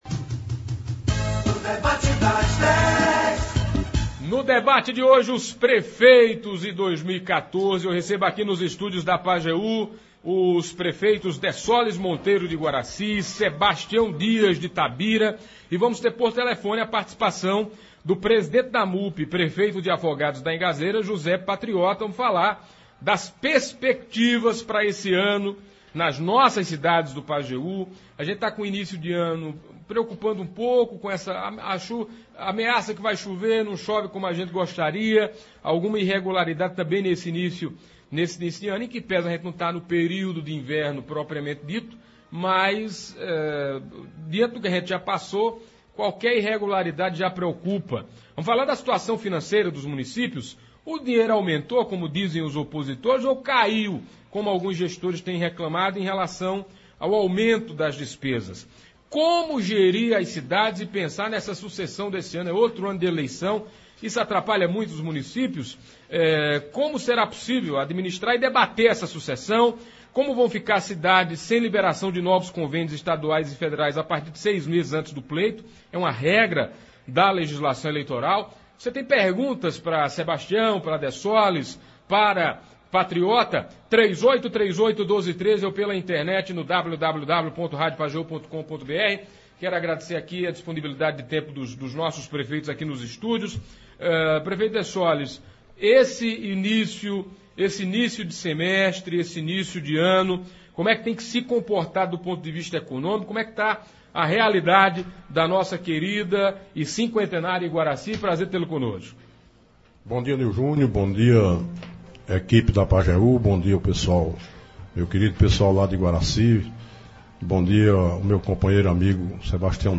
Hoje nos estúdios da Pajeú, os prefeitos, Dessoles Monteiro (Iguaracy), Sebastião Dias (Tabira) e por telefone o prefeito de Afogados da Ingazeira e também presidente da Amupe, José Patriota, falaram sobre as perspectivas para o ano de 2014.